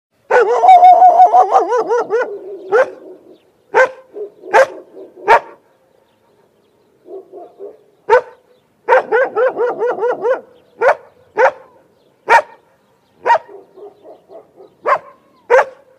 Звук - Сторожевой пес 2
Отличного качества, без посторонних шумов.